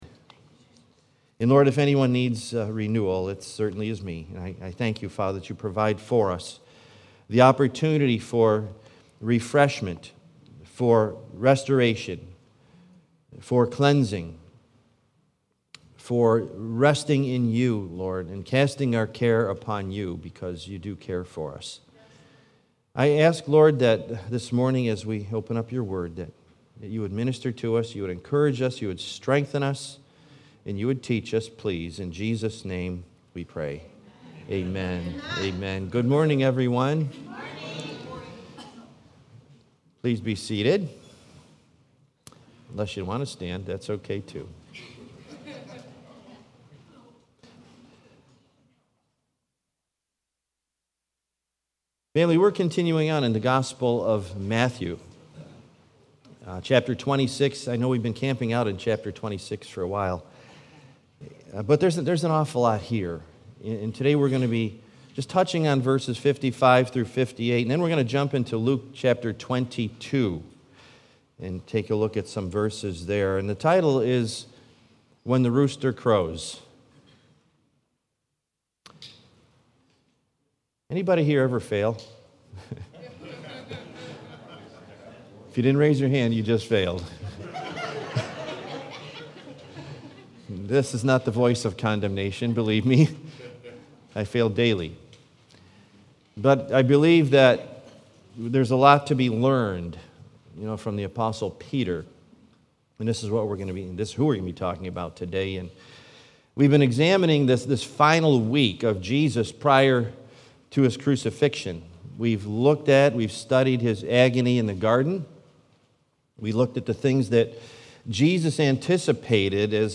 Series: Sunday Morning